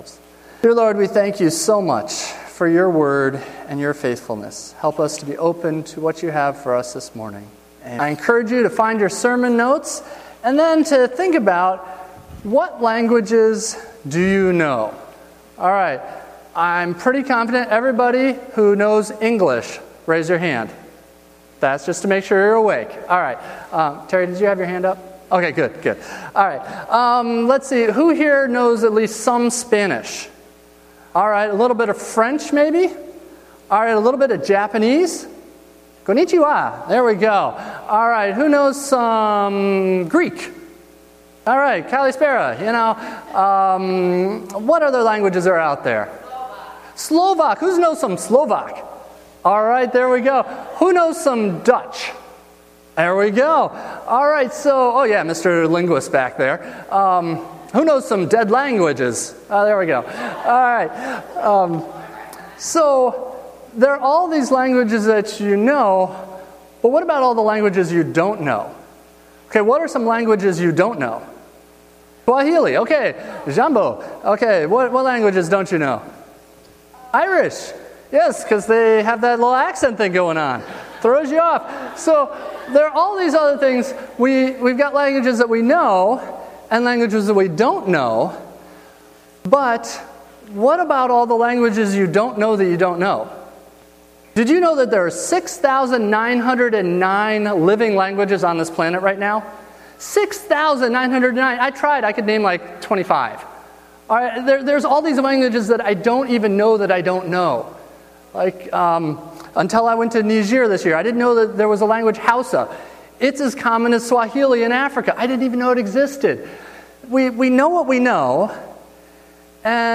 " Click for SERMON NOTES Click to see "know, don't know" chart referenced in sermon.